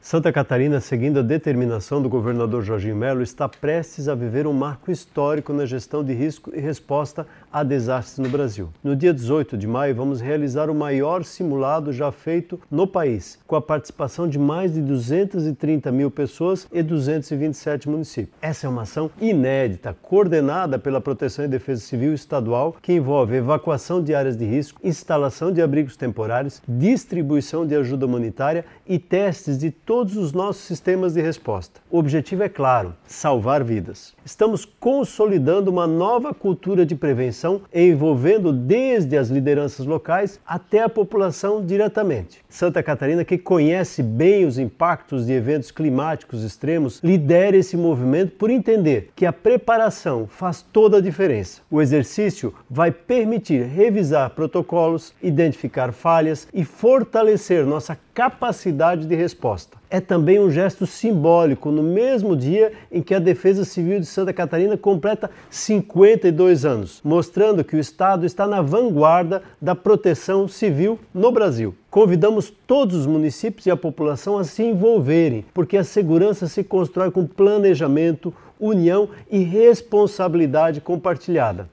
O secretário de Estado da Proteção e Defesa Civil, Mário Hildebrandt, ressalta a importância da ação para ter um sistema verdadeiramente integrado e preparado: